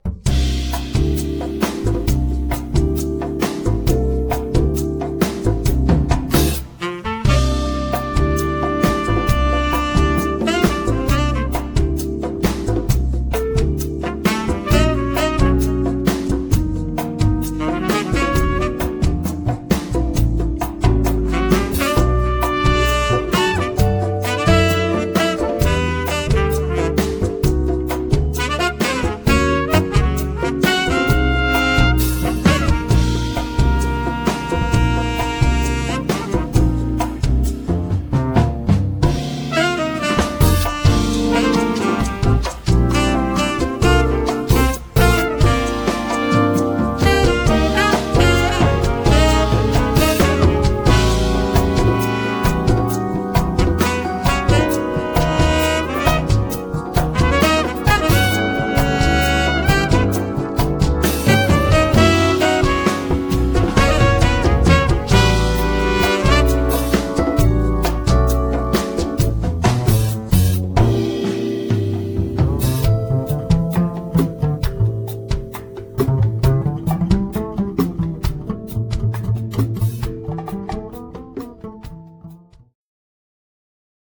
percussion
piano
bass
saxophone
trumpet
drums
guitar